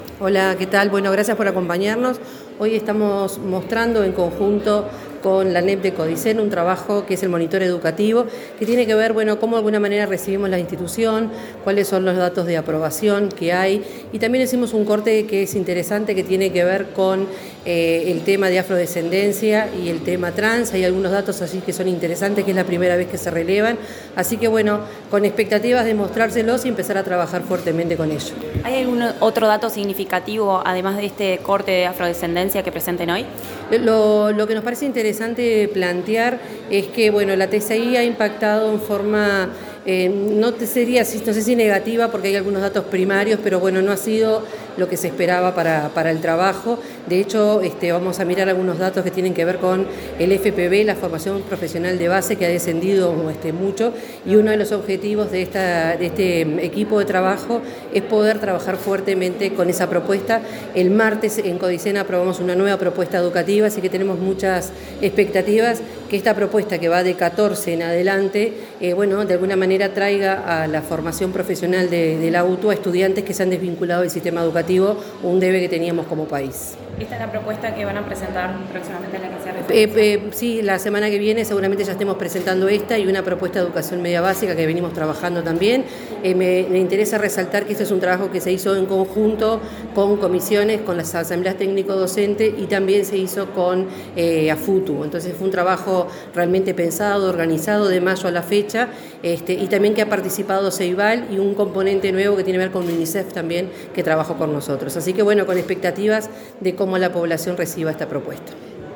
Declaraciones de la directora general de Educación Técnico Profesional, Virginia Verderese
Declaraciones de la directora general de Educación Técnico Profesional, Virginia Verderese 21/11/2025 Compartir Facebook X Copiar enlace WhatsApp LinkedIn Antes de participar en la presentación del Monitor Educativo para el período 2024-2025, la directora general de Educación Técnico Profesional, Virginia Verderese, dialogó con la prensa.